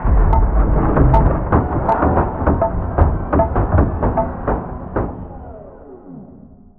upgrade.wav